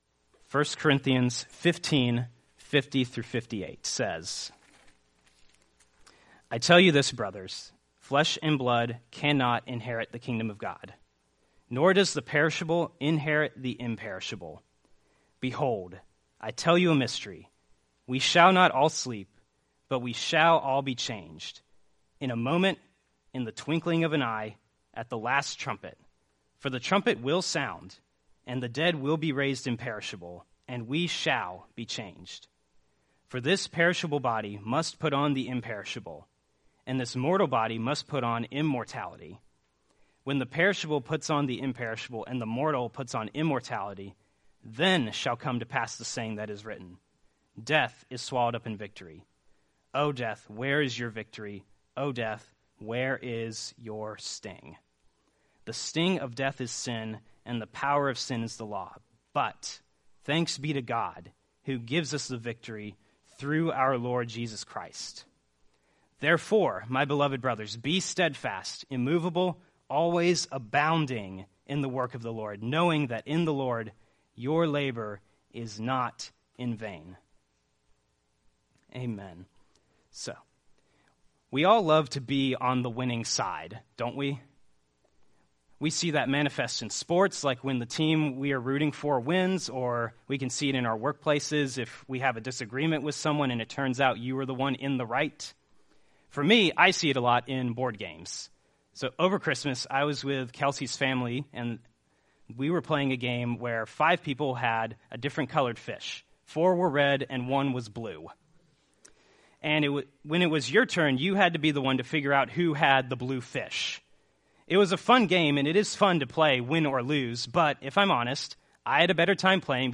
Series: One-Off Sermons